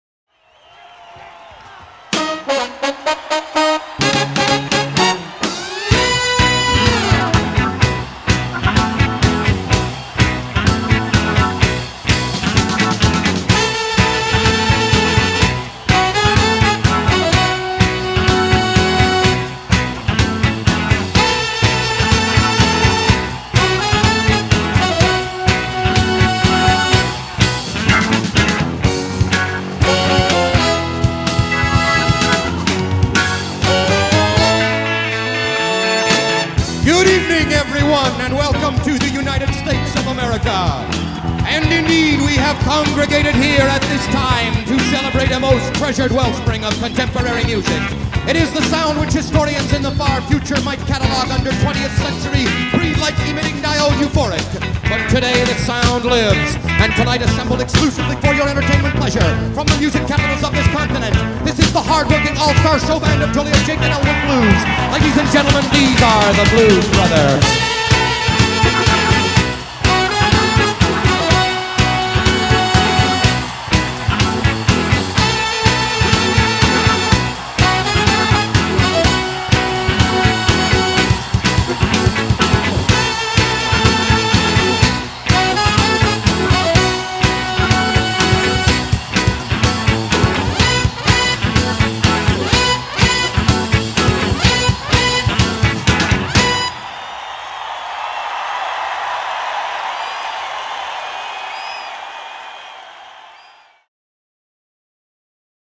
Tempo : 115
Riff Guitare - 8 mes